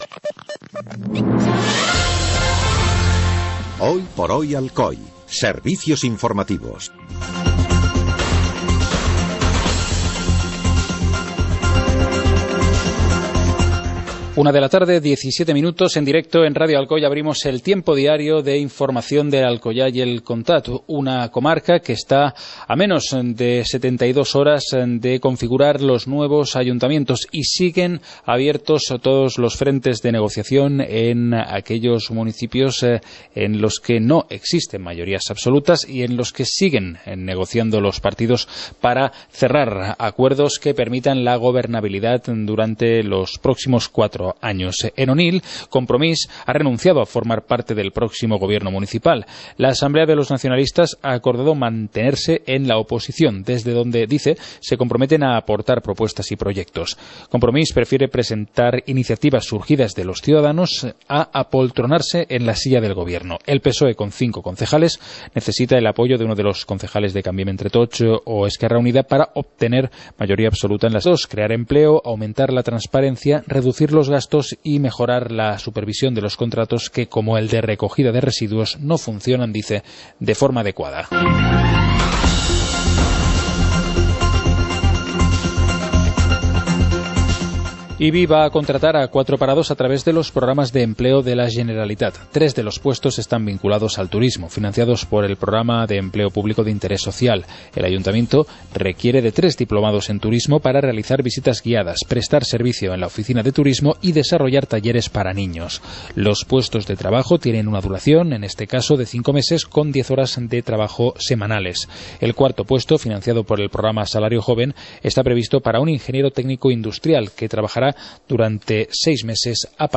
Informativo comarcal - miércoles, 10 de junio de 2015